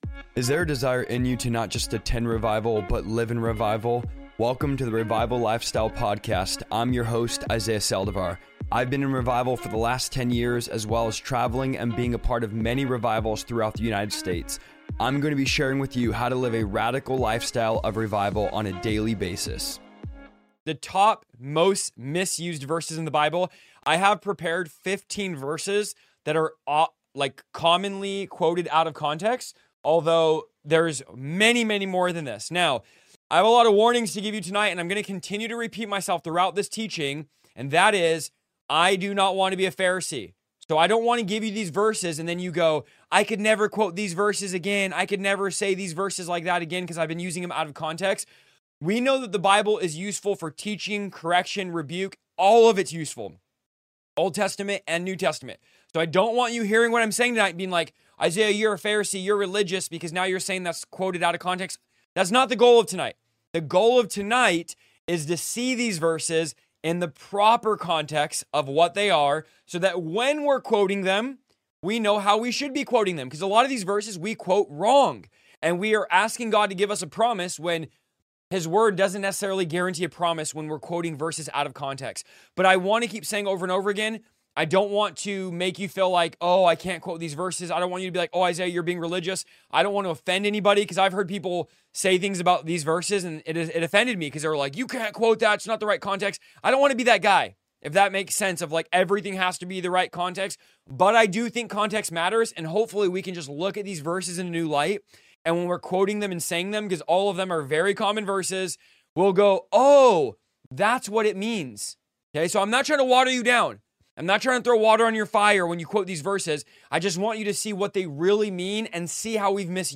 In this powerful 1-hour livestream, we’re diving deep into some of the most commonly misused verses in Scripture—and uncovering their true, in-context meanings.